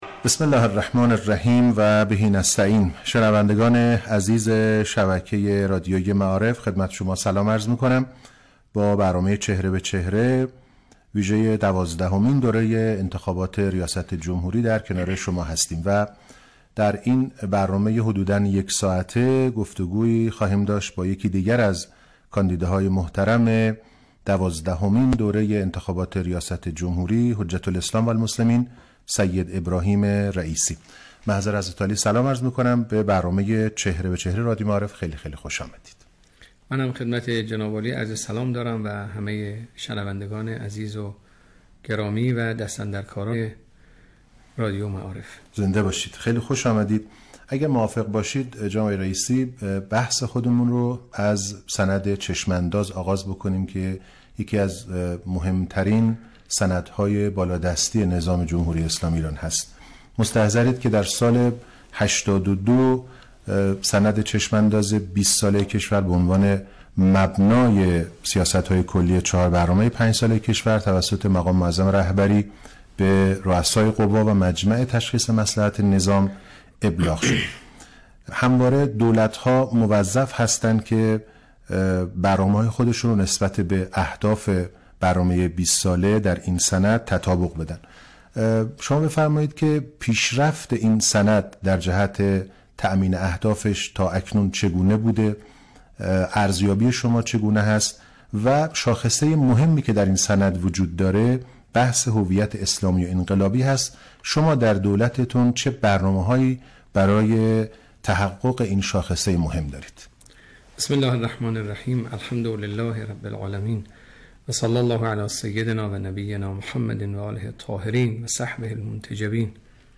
به گزارش خبرنگار سیاسی خبرگزاری تسنیم، حجت‌الاسلام سیدابراهیم رئیسی یکی از کاندیدای دوازدهمین دوره انتخابات ریاست جمهوری عصر امروز در گفتگویی با رادیو معارف طی سخنانی اظهار داشت: باید سه نگاه تغییر کند تا این تغییر نگاه منجر به تغییر در عملکرد و نتایج شود؛ نخست نگاه به بیگانه است.